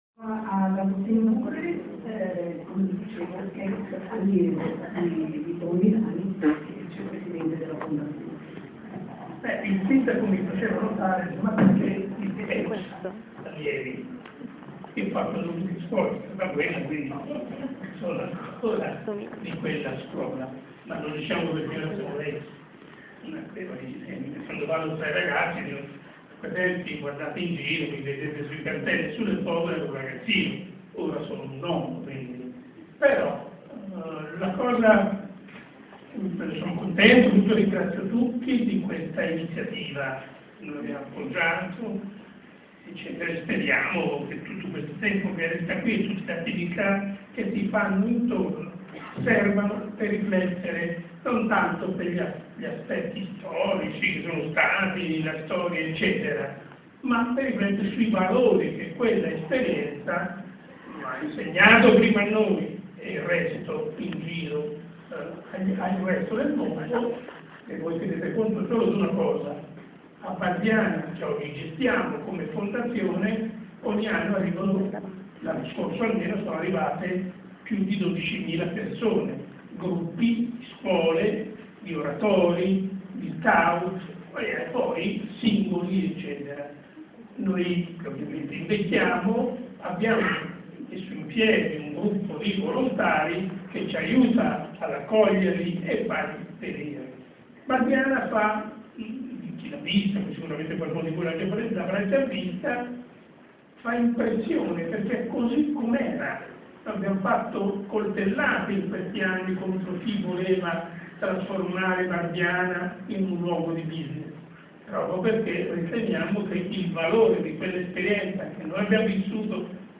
Un pubblico delle grandi occasioni quello che stasera, sabato 4 febbraio, a Palazzo delle Paure ha partecipato all’inaugurazione della Mostra su Don Milani “Il silenzio diventa voce”; che si protrarrà fino al 30 aprile.